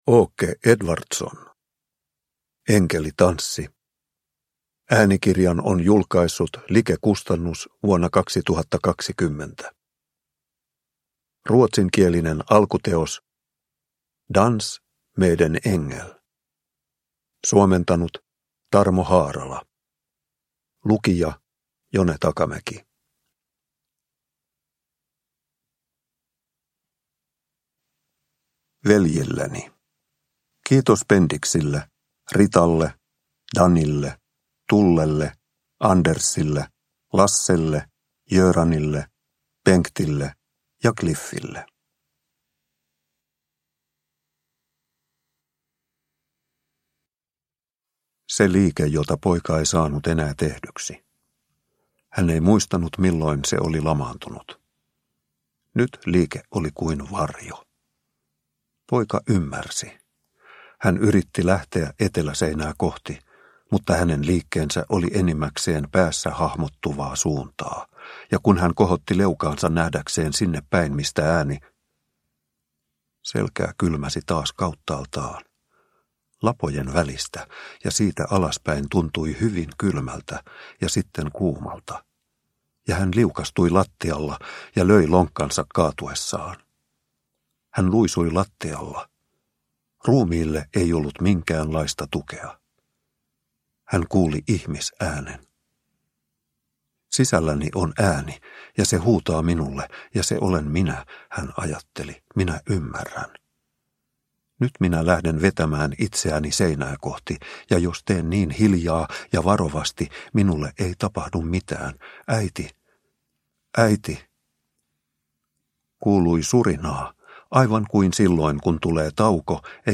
Enkelitanssi – Ljudbok – Laddas ner